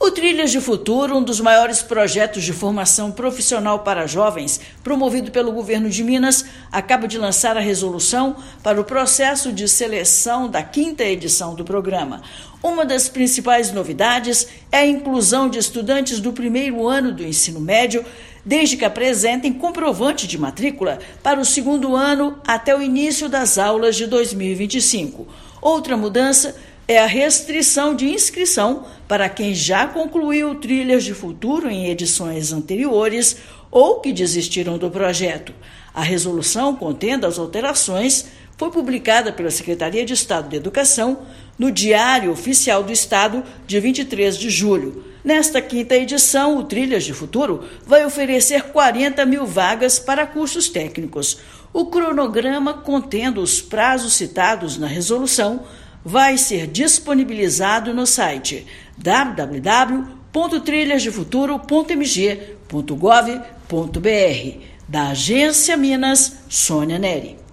Resolução detalha todos os passos para que os interessados tenham a oportunidade de uma formação gratuita e acesso ao mercado de trabalho. Ouça matéria de rádio.